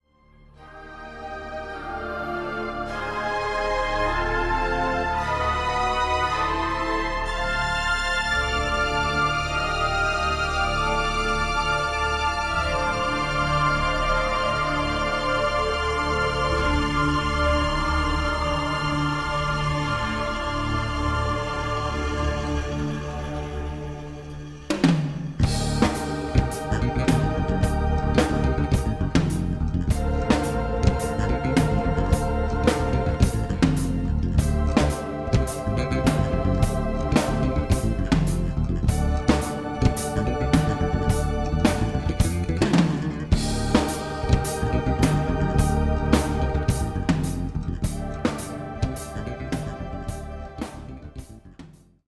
piano and synths
guitar
bass
drums